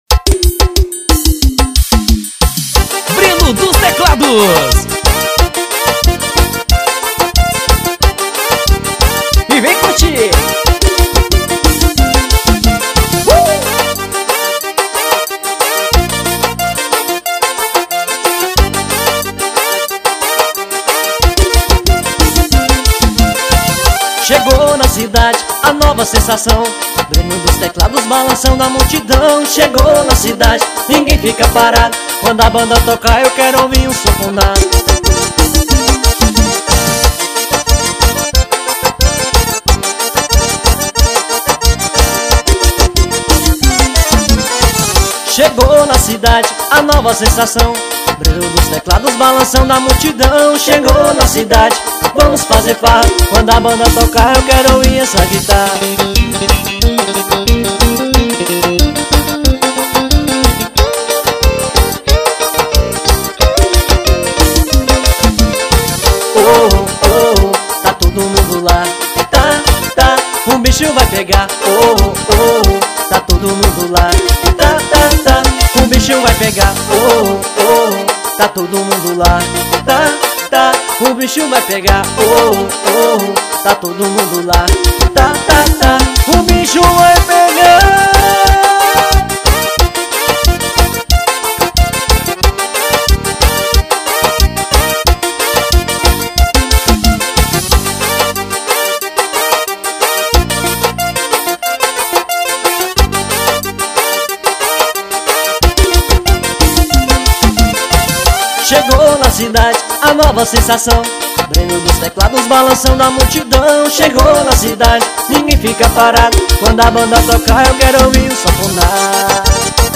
Forro xinelado.